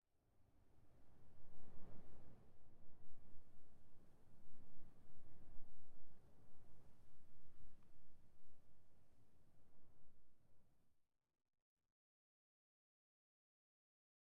Minecraft Version Minecraft Version 25w18a Latest Release | Latest Snapshot 25w18a / assets / minecraft / sounds / block / dry_grass / wind10.ogg Compare With Compare With Latest Release | Latest Snapshot
wind10.ogg